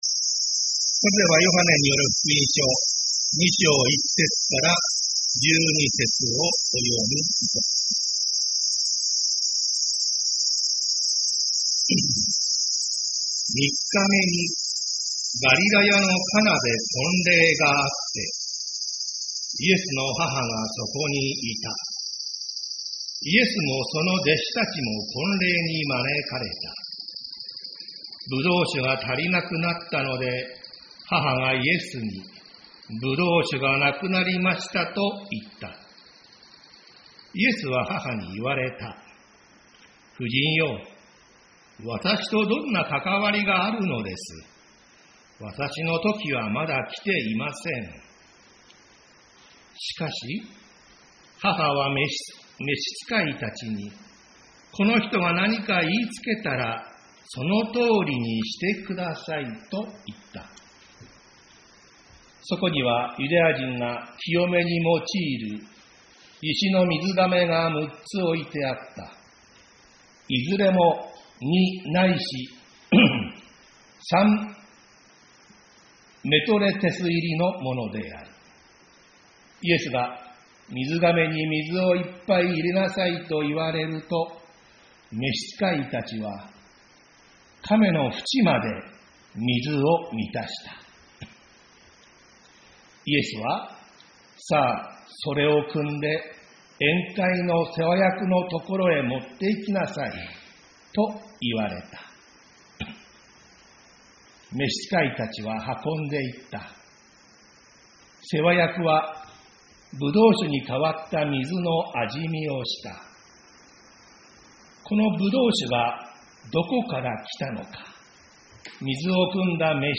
栃木県鹿沼市のプロテスタント教会。
日曜 朝の礼拝